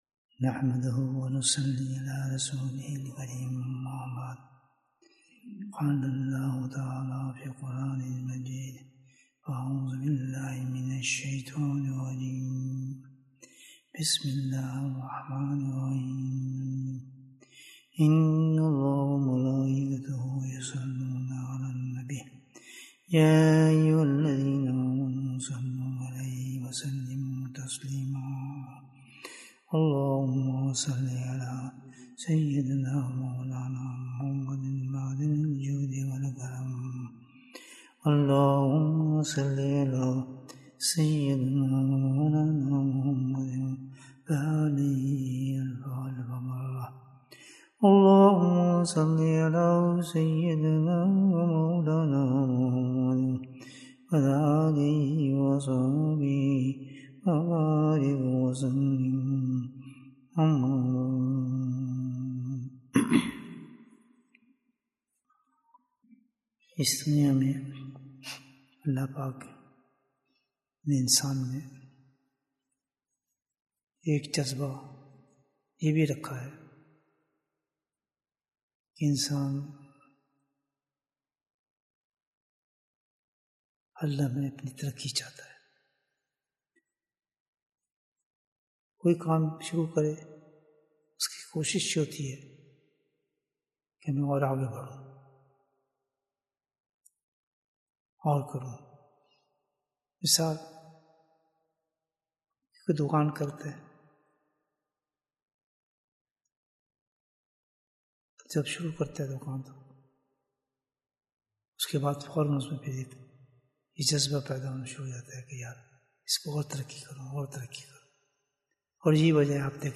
Bayan, 61 minutes 24th November, 2022 Click for English Download Audio Comments We love you hazrat sahib 14th Dec, 2022 What is the Secret to Success in Tasawwuf?